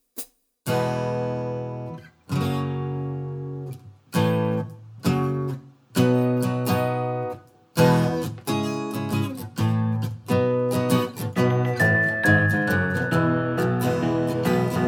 Extended No Kids, No Teacher Comedy/Novelty 1:27 Buy £1.50